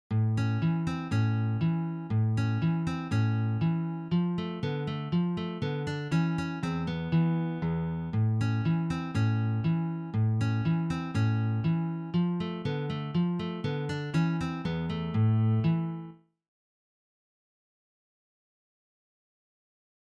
Sololiteratur
Gitarre (1)
Frisch und munter stellt sich dieser Ragtime dar.